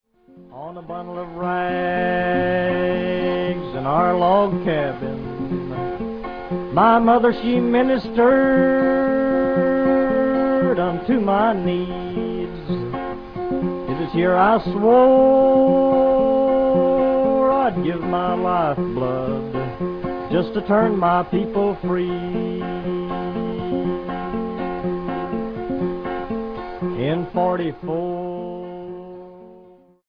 guitar
Recorded in New York between 1944 and 1949.